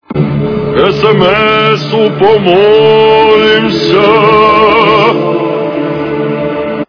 При прослушивании Священник SMS - у помо-о-о-олимся качество понижено и присутствуют гудки.
Звук Священник SMS - у помо-о-о-олимся